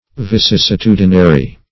Vicissitudinary \Vi*cis`si*tu"di*na*ry\, a. Subject to vicissitudes.